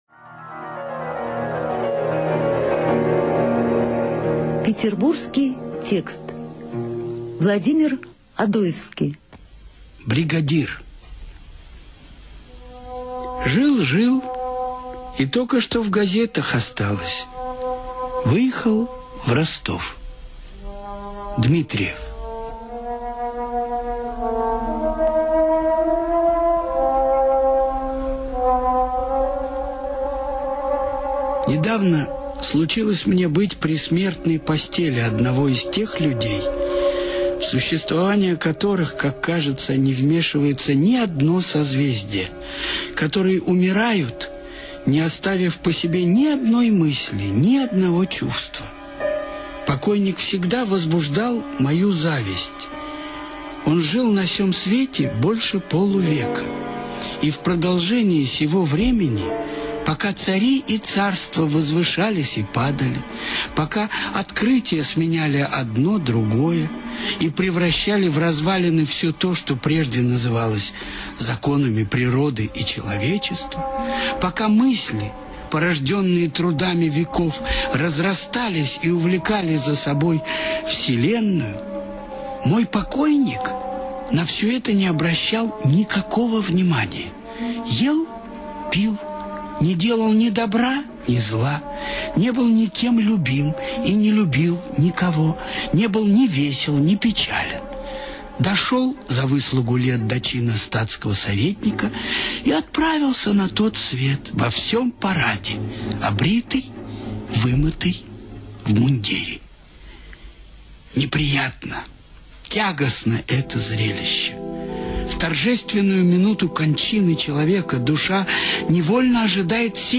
ЧитаетДрейден С.